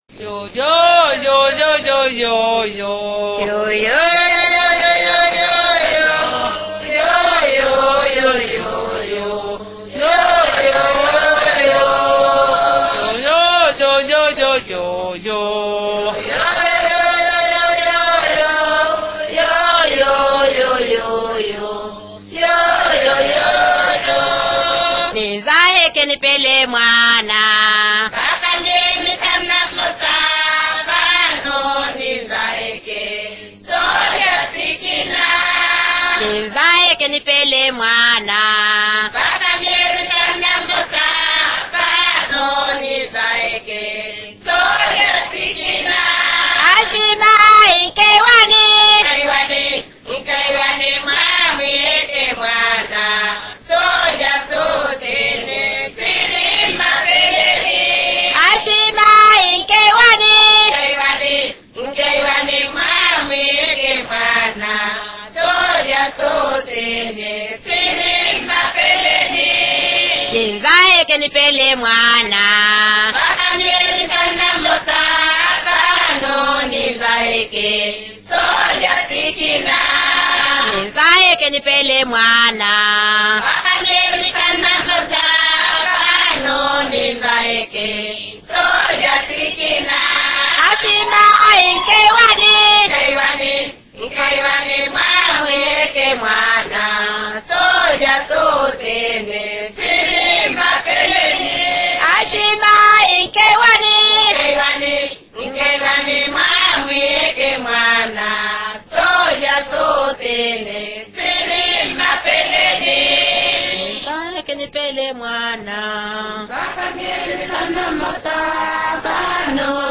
Seeing the powerful connection between the repetition of songs and social and behavior change, MCSP aimed to expand their reach further, collaborating with another USAID-funded project – the Mozambique Media Strengthening Program (MSP) – and the Radio of Monapo to record the songs in Emakwa, Nampula’s dialect.